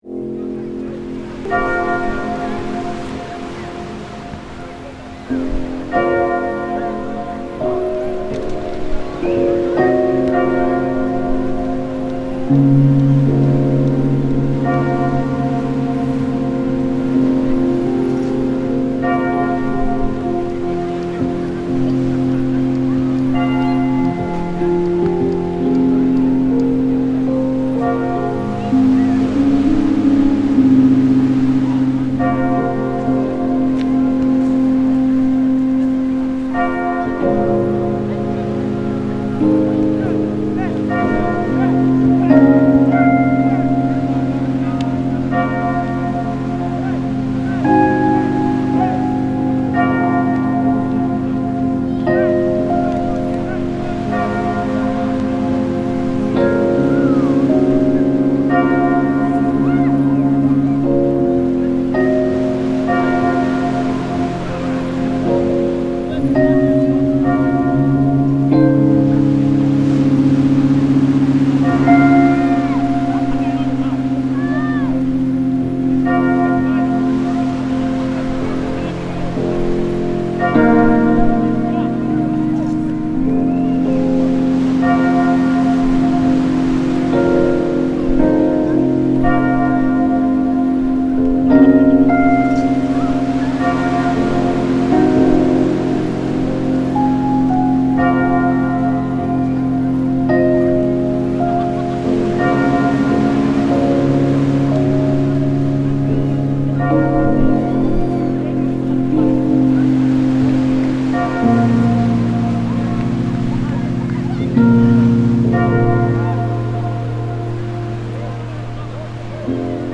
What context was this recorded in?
Scratchy Bottom beach, Dorset, England reimagined